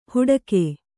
♪ huḍake